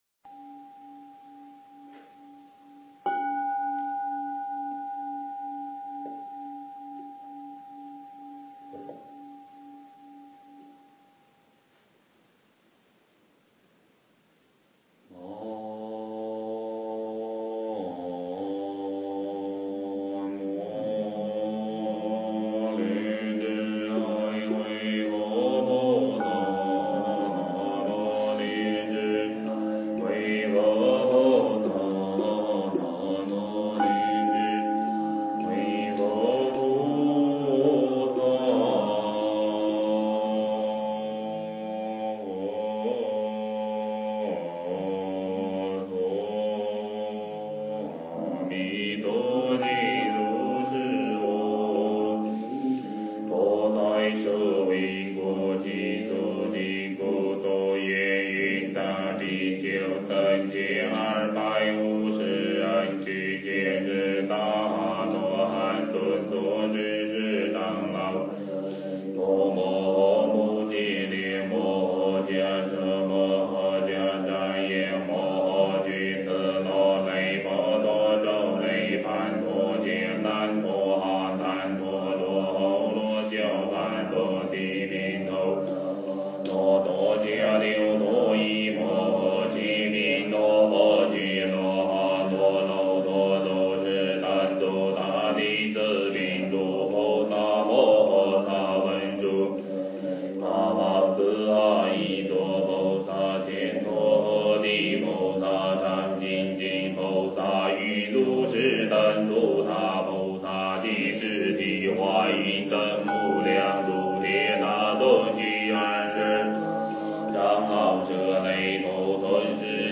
阿弥陀经念诵
经忏
佛音 经忏 佛教音乐 返回列表 上一篇： 晚课-莲池赞--未知 下一篇： 浴佛颂--寺院 相关文章 南无本师释迦牟尼佛--浴佛颂 南无本师释迦牟尼佛--浴佛颂...